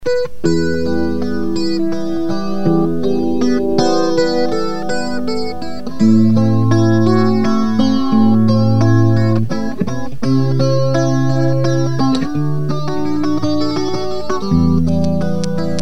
CUBE-30 Acoustic + Chorus.mp3 (189 Kb)
CUBE-30_Acoustic_Chorus.mp3